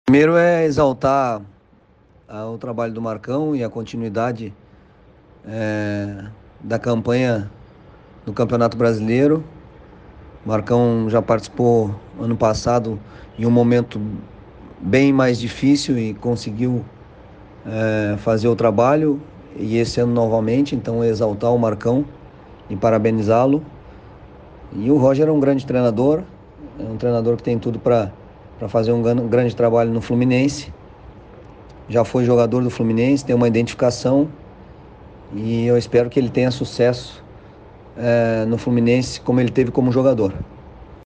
O técnico Odair Hellmann treinador do Al Wasl, dos Emirados Árabes, em entrevista realizada na Super Rádio Tupi, no Show da galera, falou sobre sua adaptação no time Árabe, e principalmente sobre o trabalho que realizou no Fluminense.